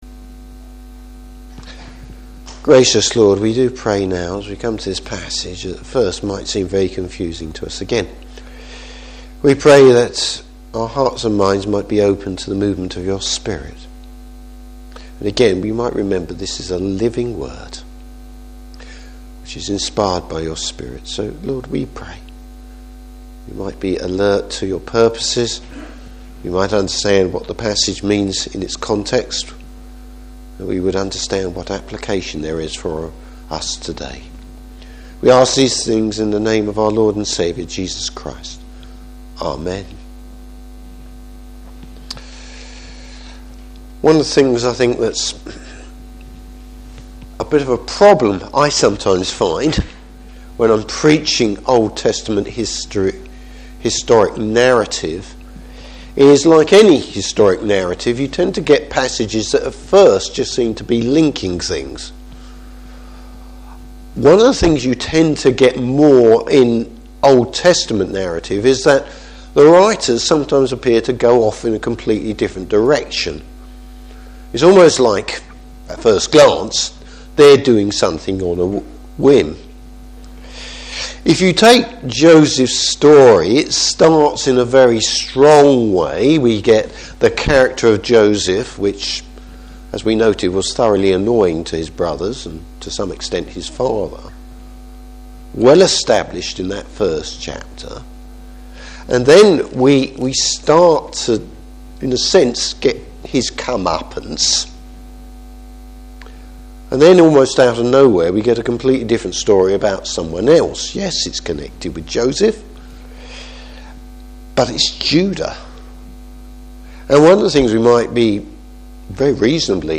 Passage: Genesis 38. Service Type: Evening Service Judah’s big fail!